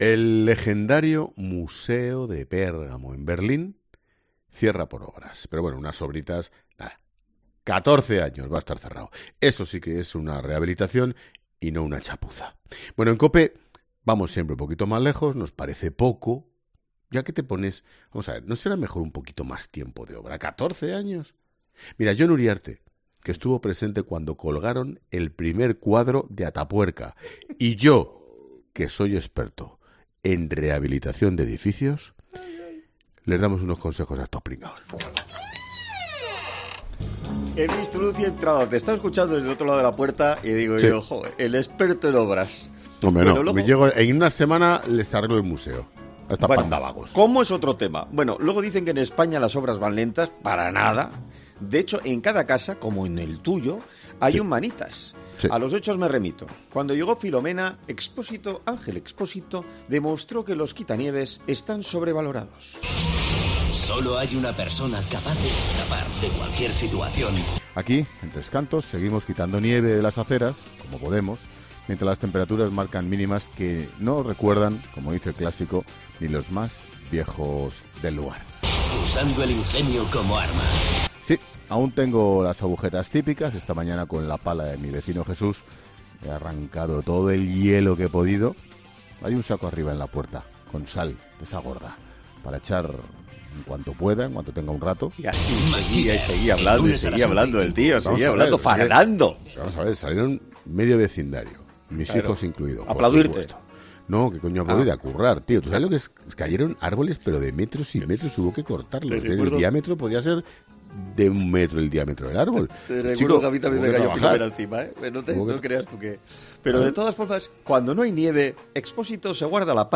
Los comunicadores recordaron algunas obras que tardaron bastantes años en terminarse y no podía faltar la 'Muralla China'.